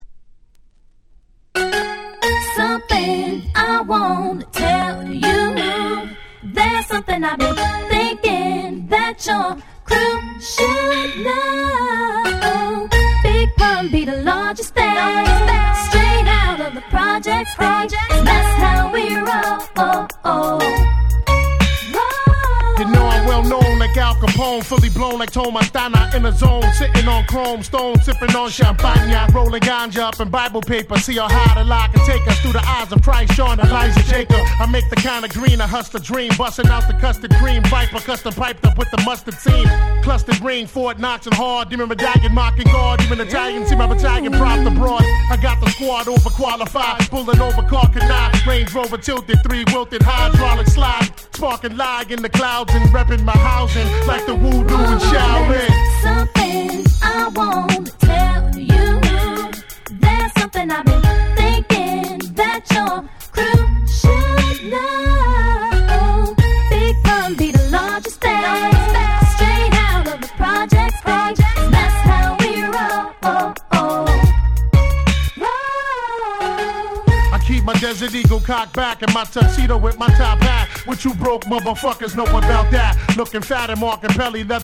01' Smash Hit Hip Hop !!